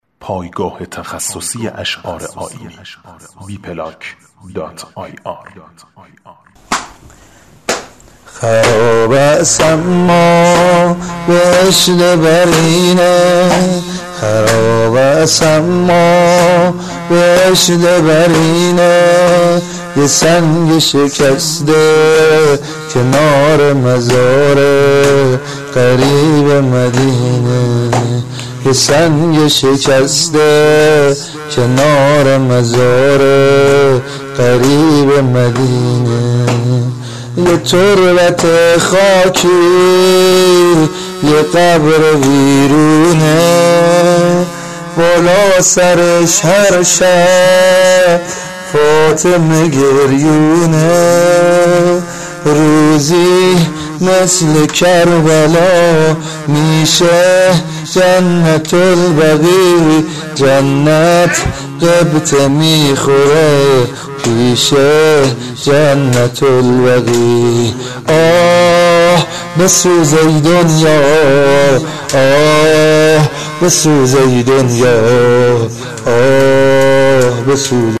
زمینه امیرحسین الفت